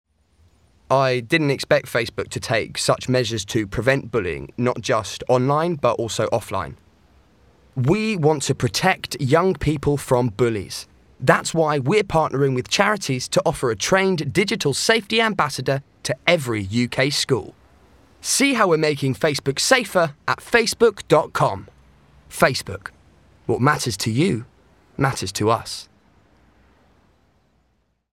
Tyger has a neutral RP accent with an upbeat, youthful and enthusiastic tone to his voice.
• Male